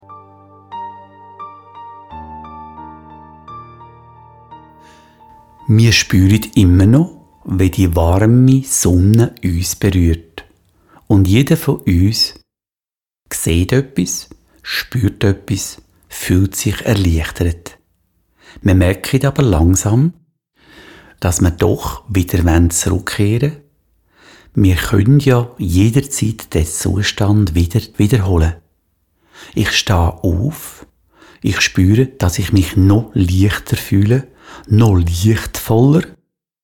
Schwyzerdütsch
Diese mp3-Download-Datei enthält Meditationen und Seelenreisen